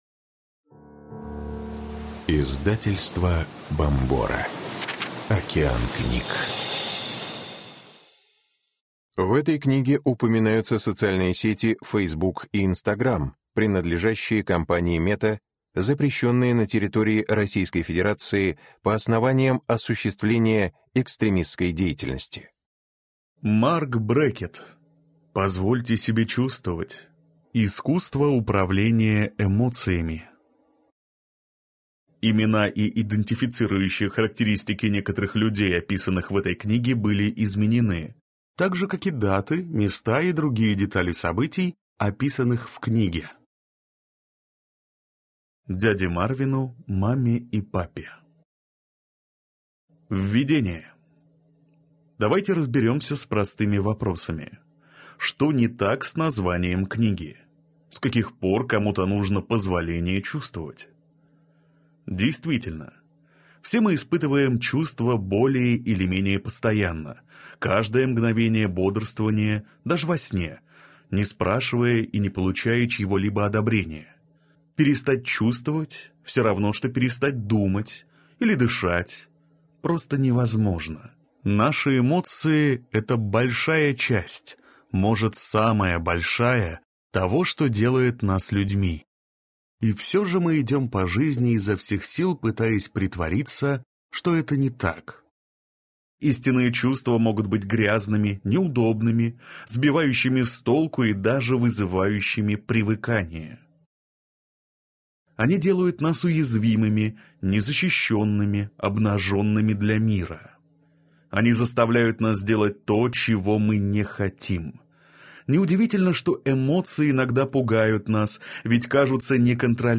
Аудиокнига Позвольте себе чувствовать. Искусство управления эмоциями | Библиотека аудиокниг